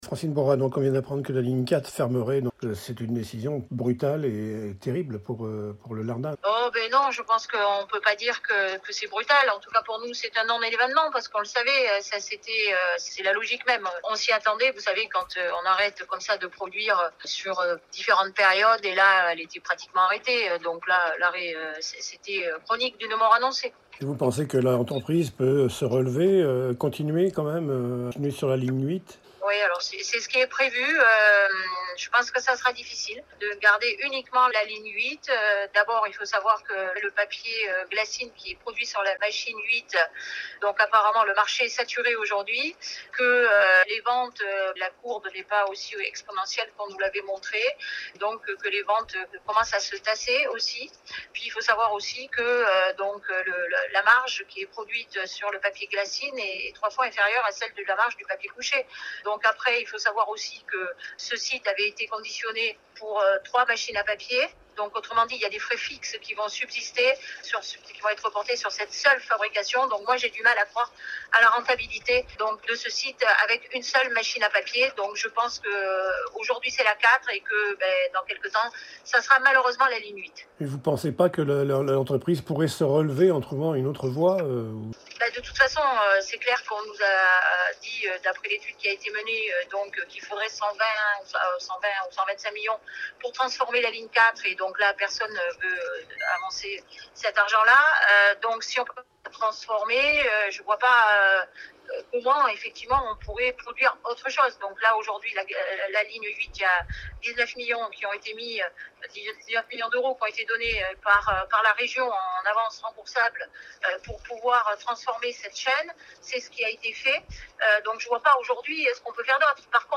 Interview audio de Francine Bourra, maire du Lardin-Saint-Lazare, le 20 juin 2023 ;